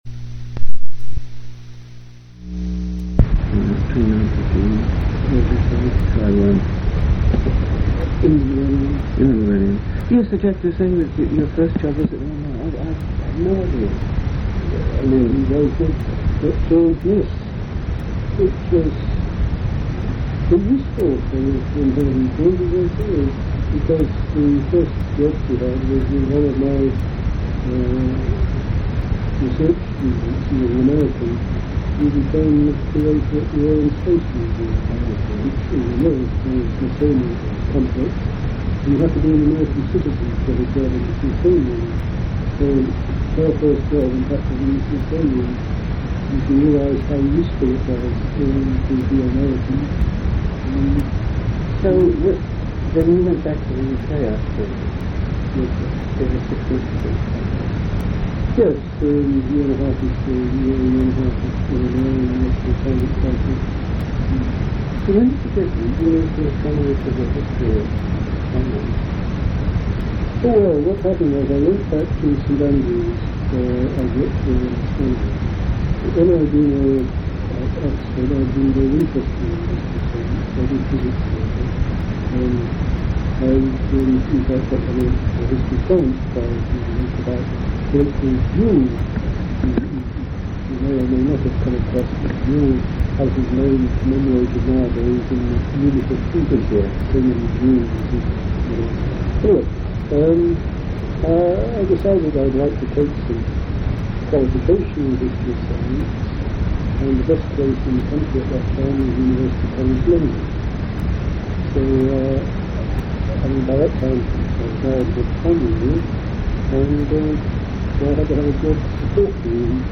Oral histories
Place of interview Loughborough University